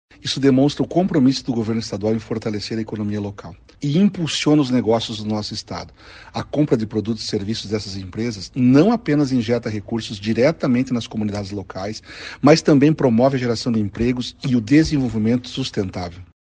Sonora do secretário da Administração e da Previdência, Elisandro Frigo, sobre as compras públicas de micro e pequenas empresas no Paraná